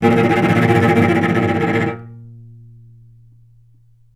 healing-soundscapes/Sound Banks/HSS_OP_Pack/Strings/cello/tremolo/vc_trm-A#2-mf.aif at 01ef1558cb71fd5ac0c09b723e26d76a8e1b755c
vc_trm-A#2-mf.aif